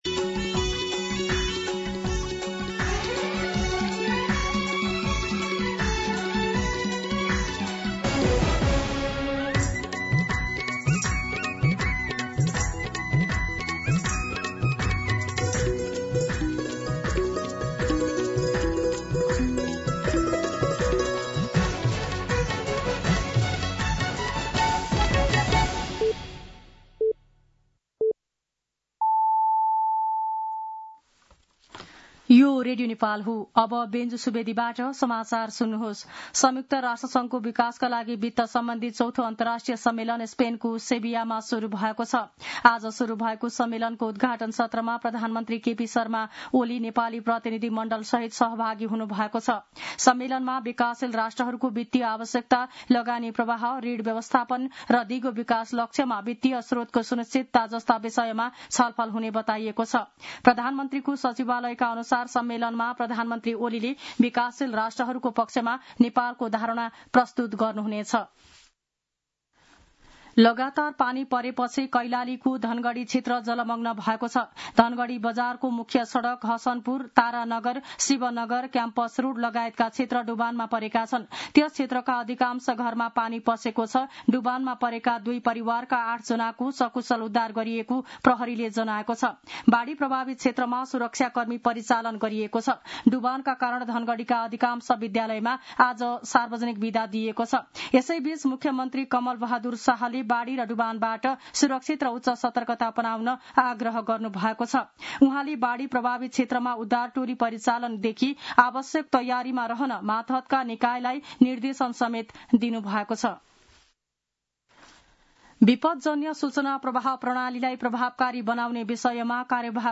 दिउँसो ४ बजेको नेपाली समाचार : १६ असार , २०८२